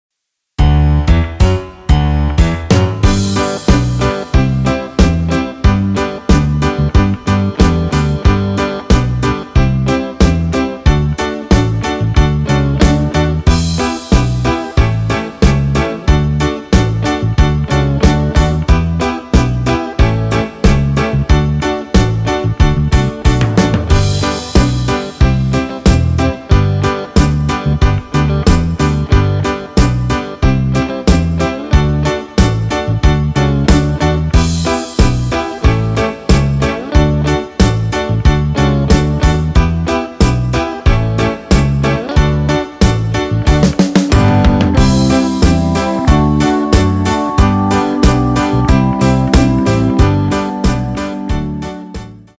Hudobné podklady MP3